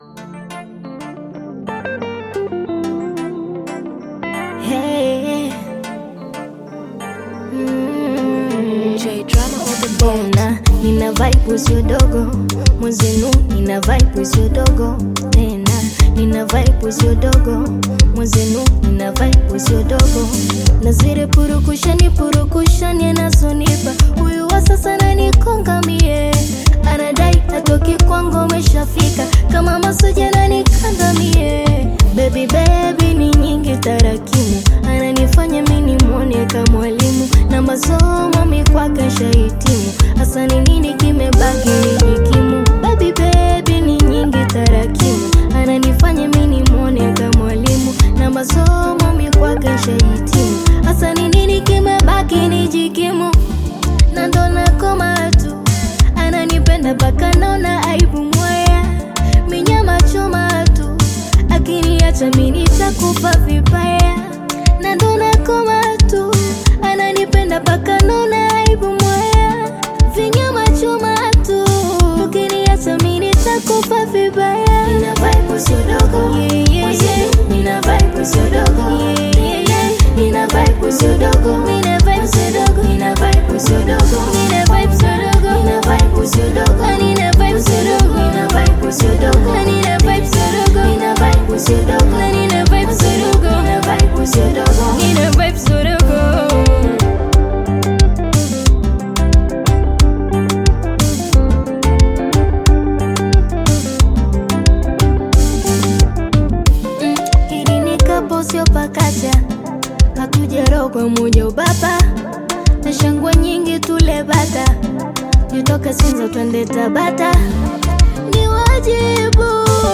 Bongo Flava music track
Tanzanian Bongo Flava artist
Bongo Flava song